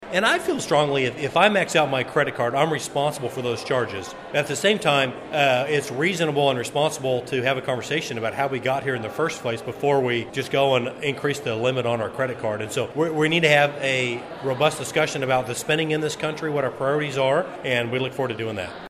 Congressman Tracey Mann met with constituents Tuesday at the Wefald Pavilion in City Park, part of his ongoing listening tour across the Big First district.